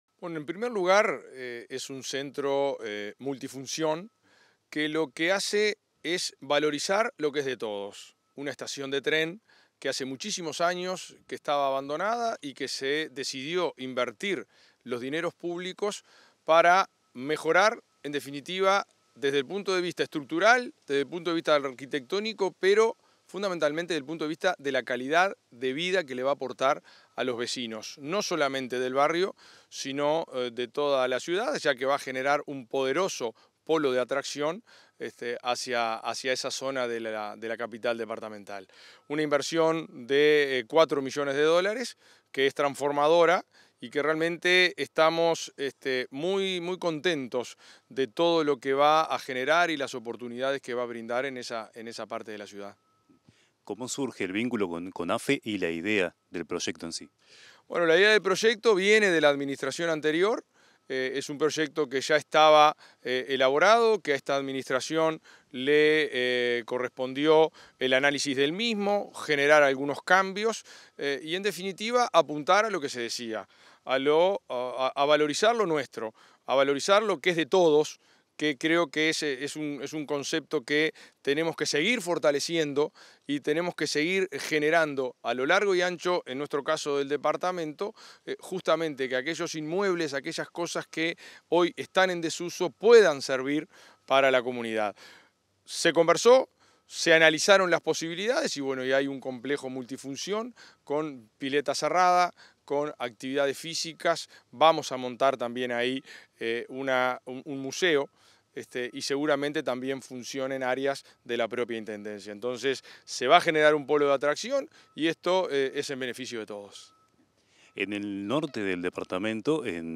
Entrevista al intendente de Lavalleja, Mario García
En diálogo con Comunicación Presidencial, el intendente de Lavalleja, Mario García, resaltó los trabajos que se realizan junto con el Fondo de Desarrollo del Interior (FDI) de la Oficina de Planeamiento y Presupuesto (OPP). En la antigua estación de AFE de Minas, avanza la construcción de un complejo social, deportivo y cultural, financiado a través del Programa de Desarrollo y Gestión Subnacional II.